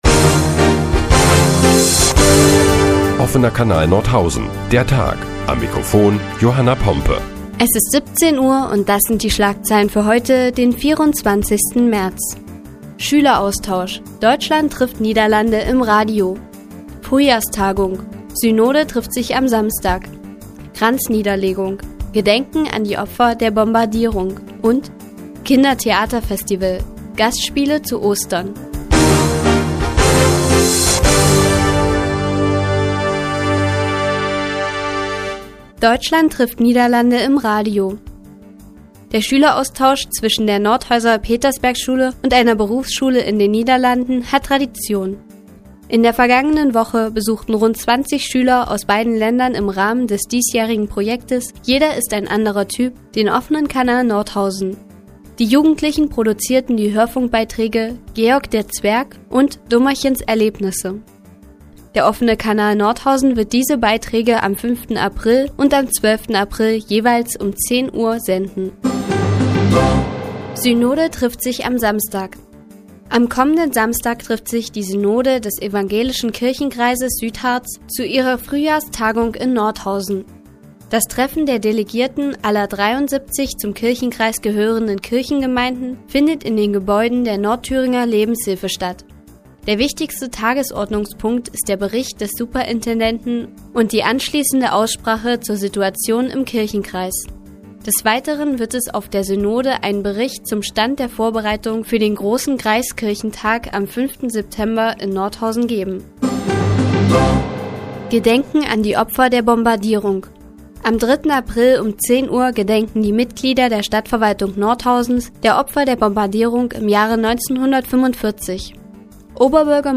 Die tägliche Nachrichtensendung des OKN ist nun auch in der nnz zu hören. Heute geht es unter anderem um die Frühjahrstagung der Synode des Evangelischen Kirchenkreises Südharz und um das Gedenken an die Opfer der Bombardierung im Jahre 1945.